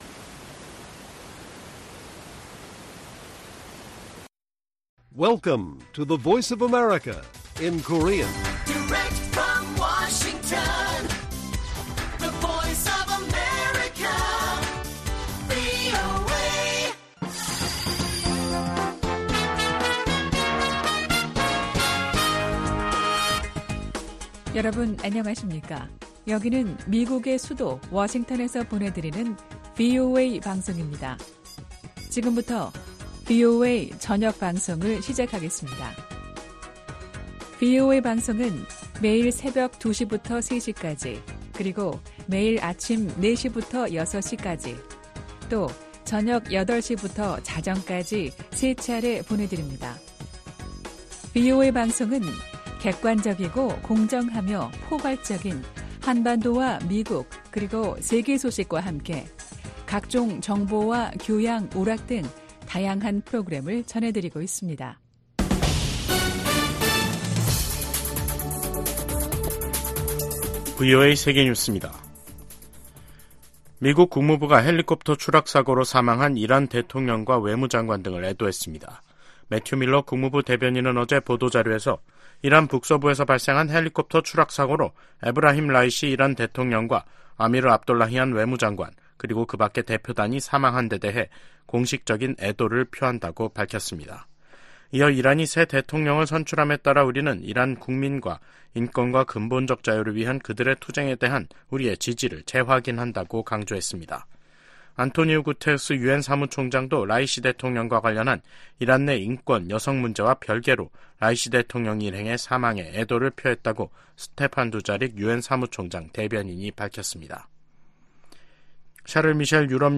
VOA 한국어 간판 뉴스 프로그램 '뉴스 투데이', 2024년 5월 21일 1부 방송입니다. 최근 북한과 러시아의 협력 강화는 중국도 우려해야 할 사안이라고 미 국무부가 지적했습니다. 미국이 유엔 무대에서 북한과 러시아 간 불법 무기 이전을 비판하면서, 서방의 우크라이나 지원을 겨냥한 러시아의 반발을 일축했습니다. 유럽연합 EU는 러시아가 중국과의 정상회담 후 북한 옹호성명을 발표한 데 대해 기회주의적인 선택이라고 비판했습니다.